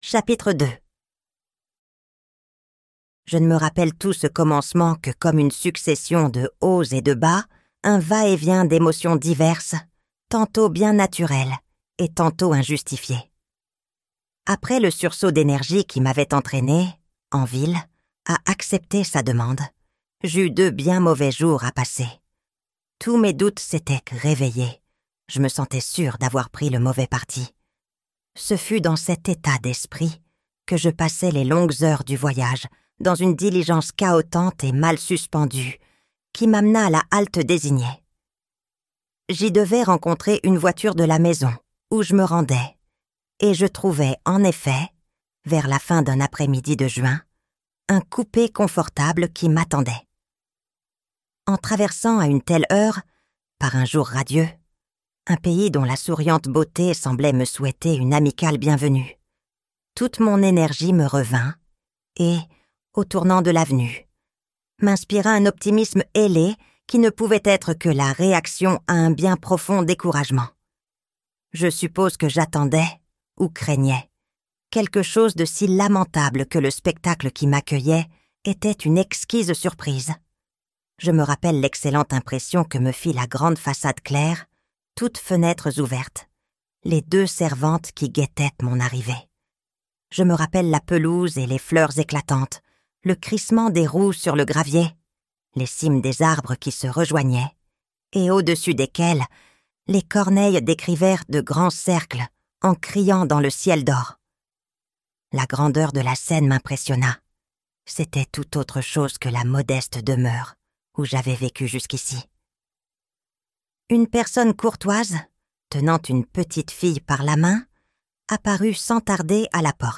Le Tour d'écrou - Livre Audio